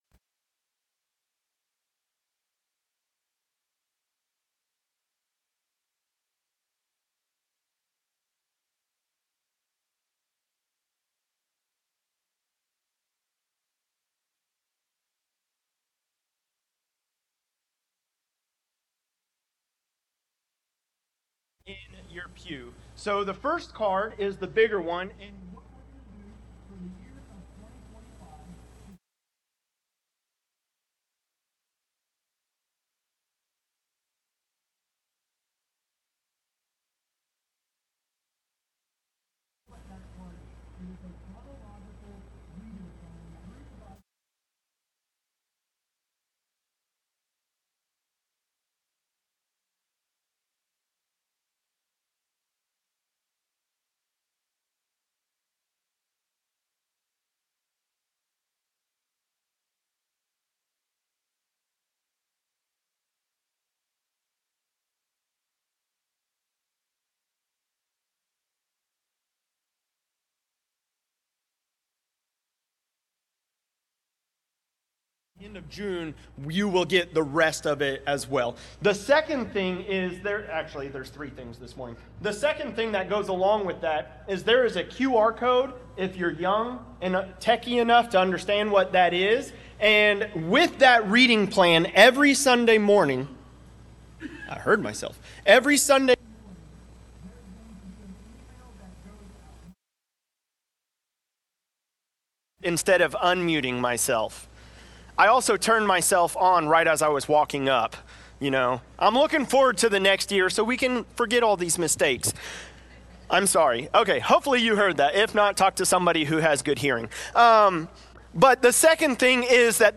Sermons by Center Christian Church